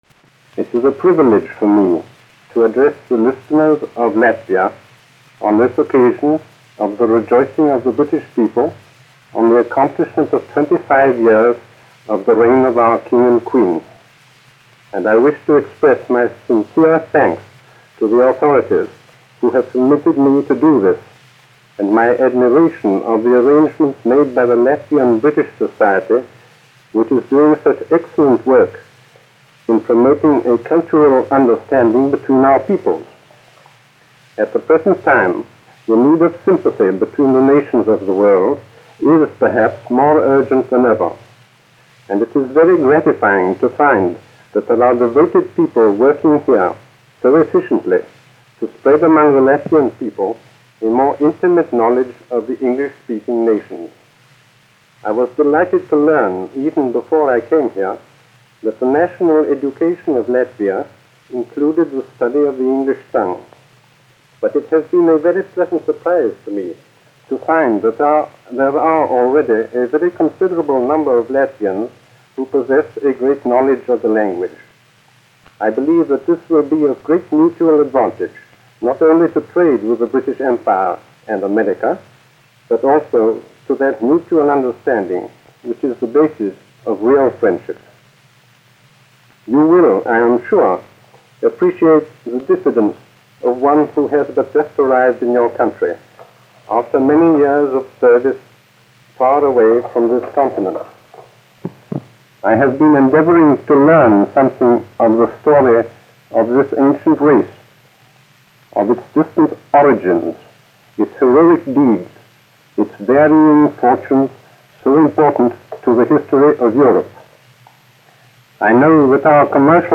2 skpl. : analogs, 78 apgr/min, mono ; 25 cm
Svētku runas -- Latvija
Ieraksta beigās tiek atskaņota Lielbritānijas himna "God save the King"
Skaņuplate